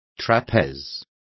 Complete with pronunciation of the translation of trapezes.